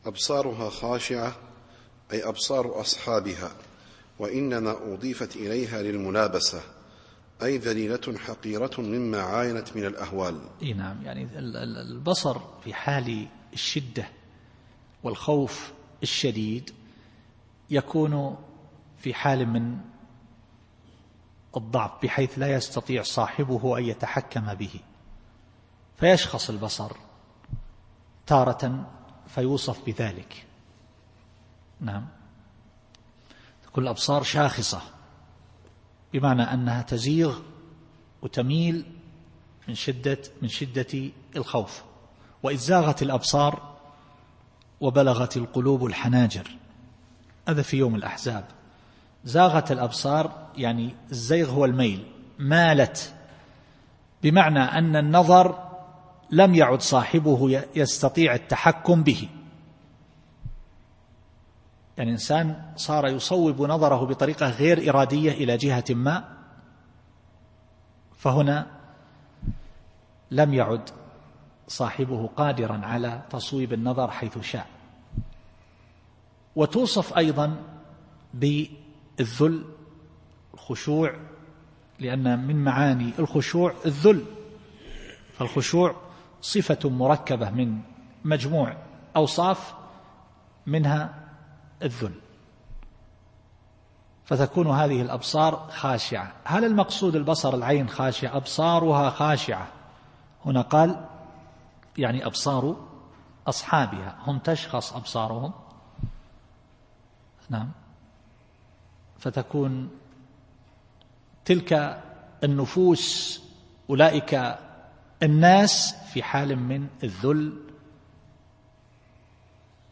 التفسير الصوتي [النازعات / 9]